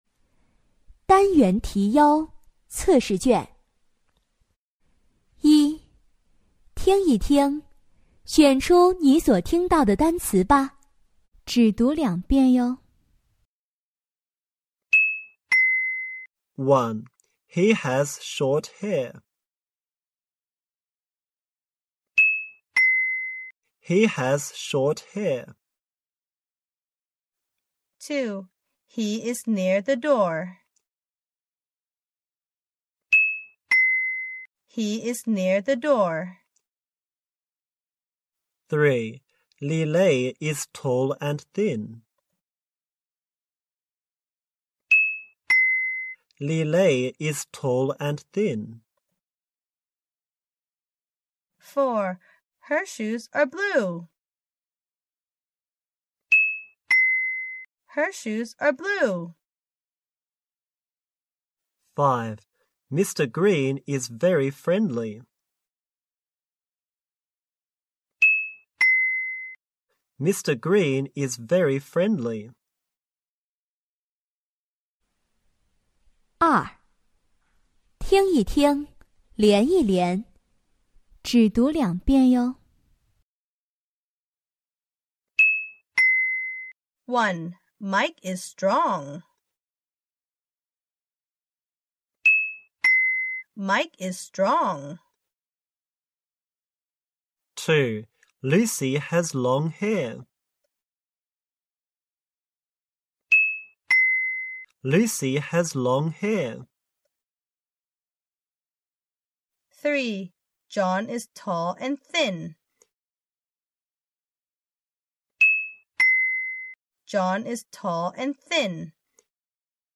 当前位置：首页 > 增值服务 > 听力MP3